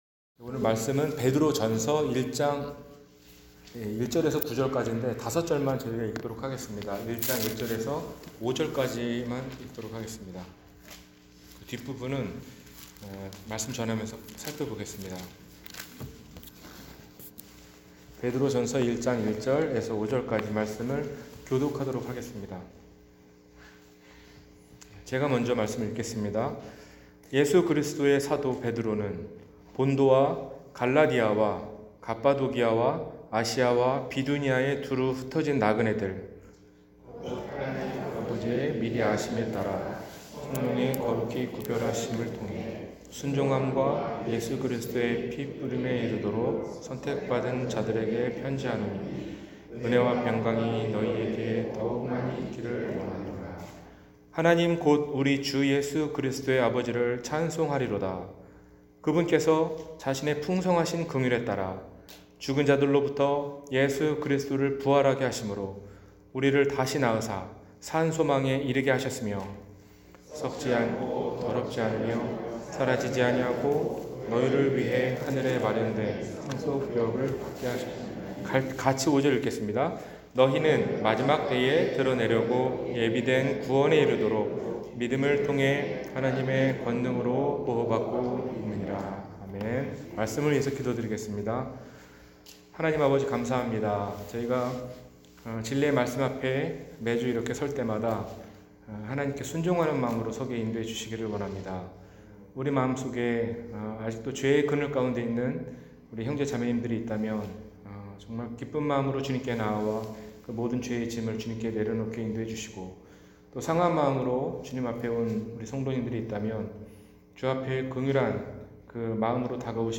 기뻐하라, 나그네여 – 주일설교